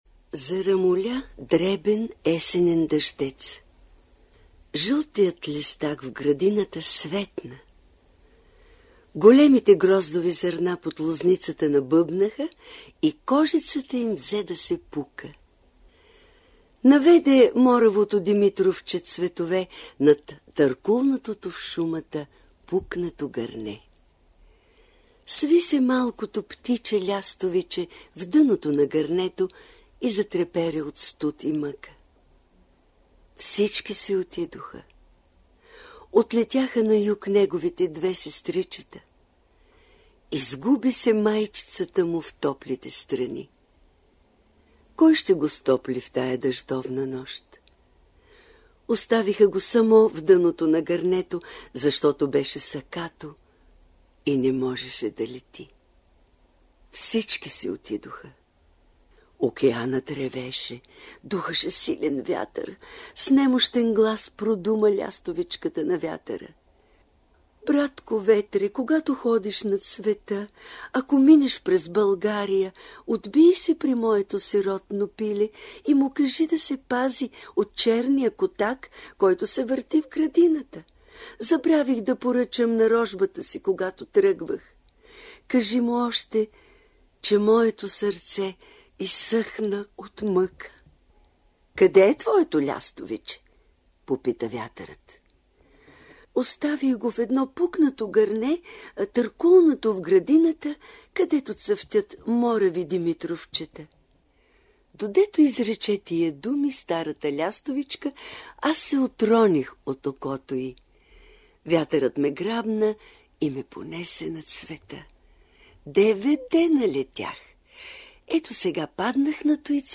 Думи, които много напомнят началото на разказа „Майчина сълза“, които е съхранен в Златния фонд на Българското национално радио (БНР). През 2007 година той е представен на слушателите с гласа на Йорданка Кузманова.